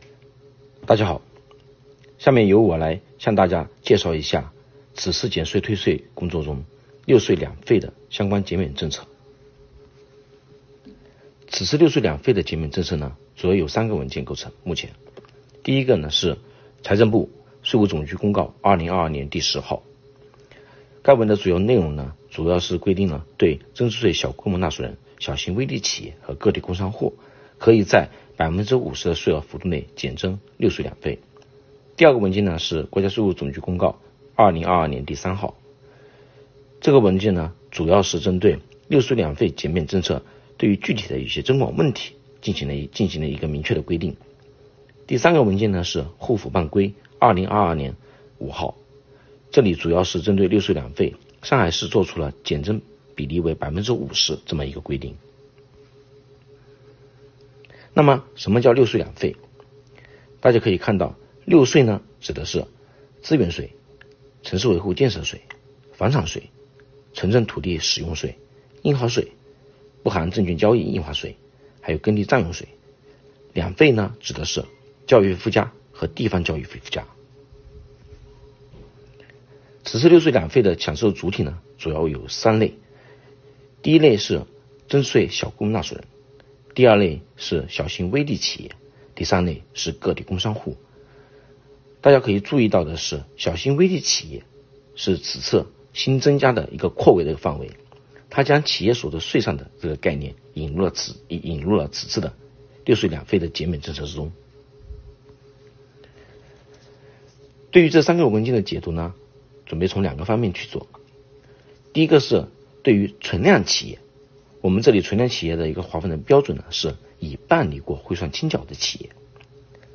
主讲人：奉贤区税务局